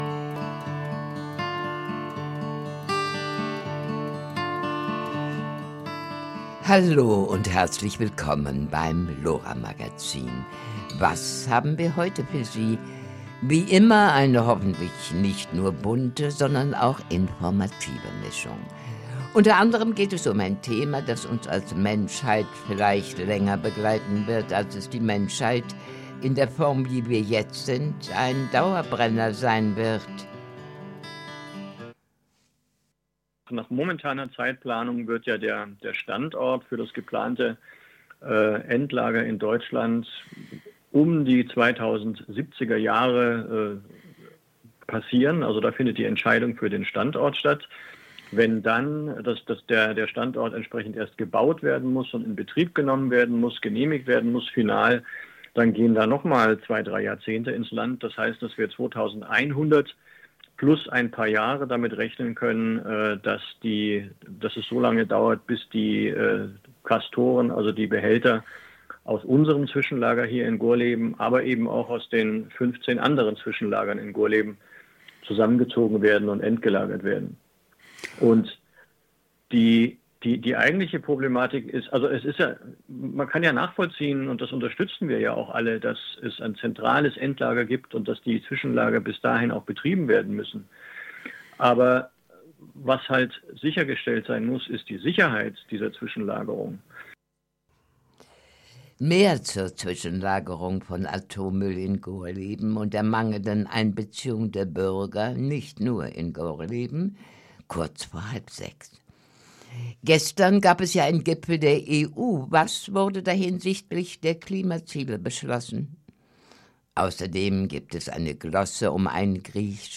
Interview mit Radio Lora 92,4